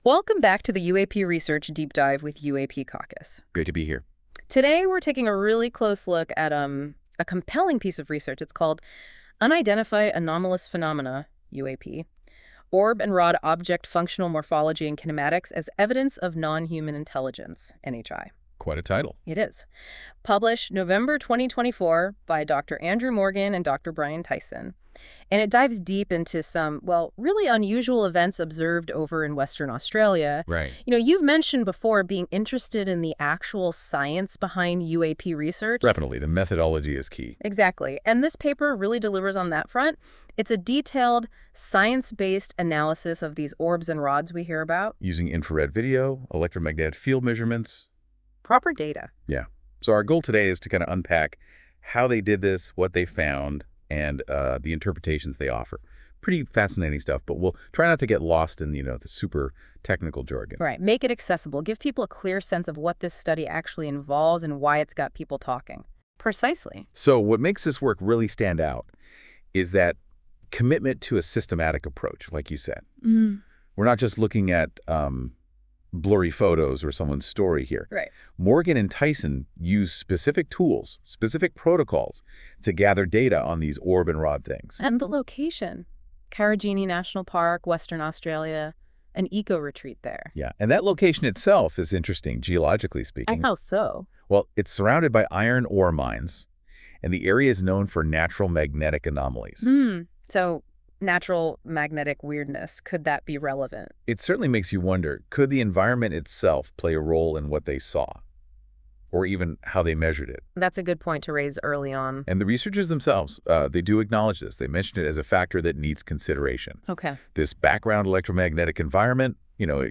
This AI-generated audio may not fully capture the research's complexity.